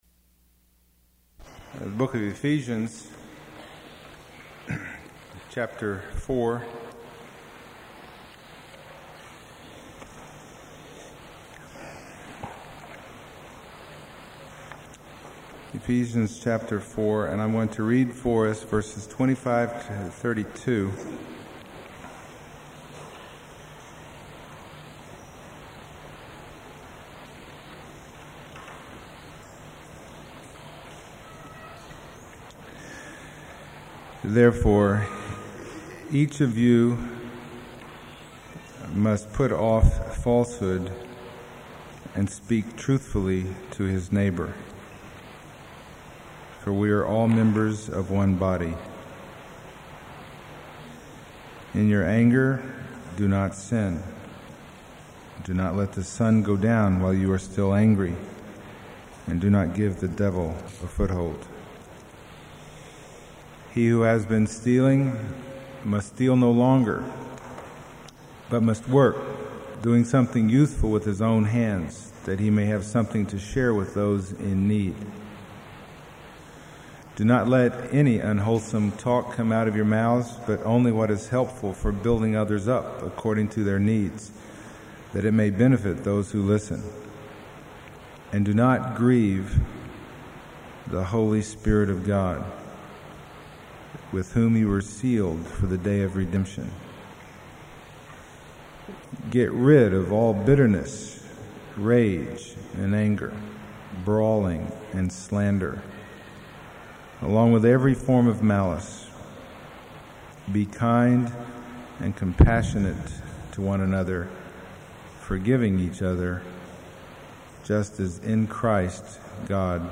Ephesians Passage: Ephesians 4:25-32 Service Type: Sunday Morning %todo_render% « Diversity and Unity in the Church Be Filled